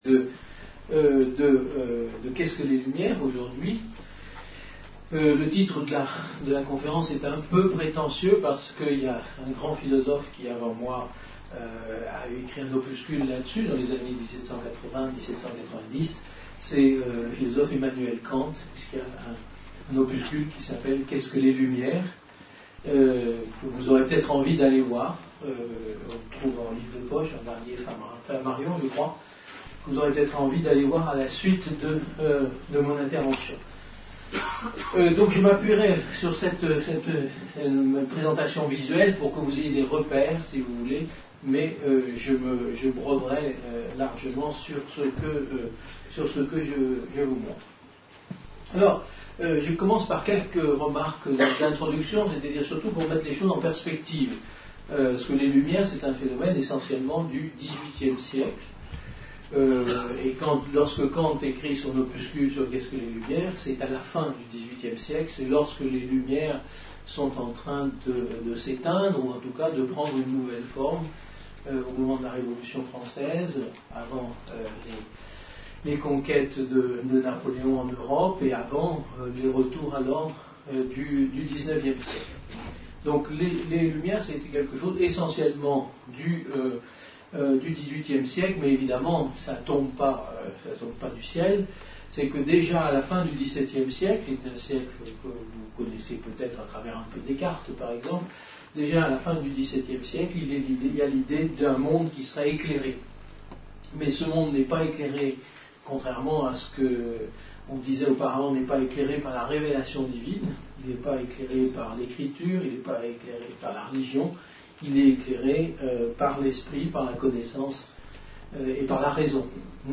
Une conférence de l'UTLS au Lycée avec Yves Michaud en partenariat avec AgrobioscienceUniversité des lycéens Lycée Théophille Gautier (Tarbes)